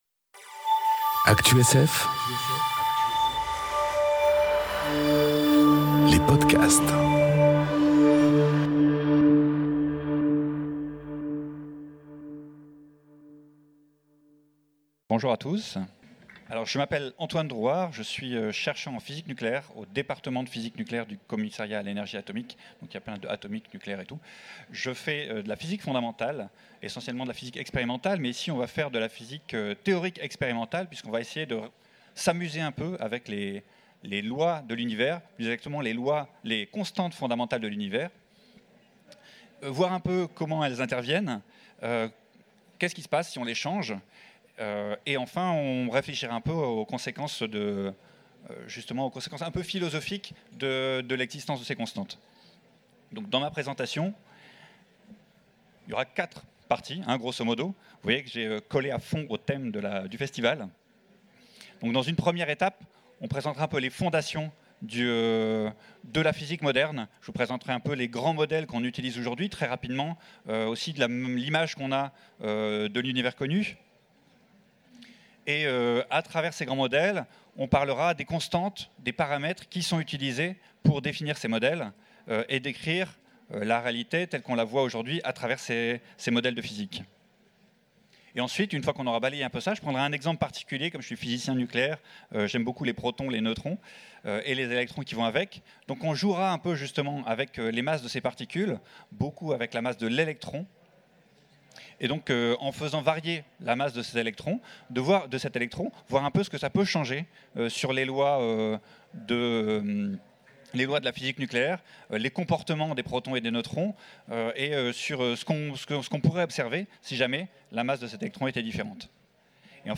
Conférence Et si les lois du monde subatomique étaient différentes ? enregistrée aux Utopiales 2018